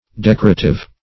Decorative \Dec"o*ra*tive\ (d[e^]k"[-o]*r[.a]*t[i^]v or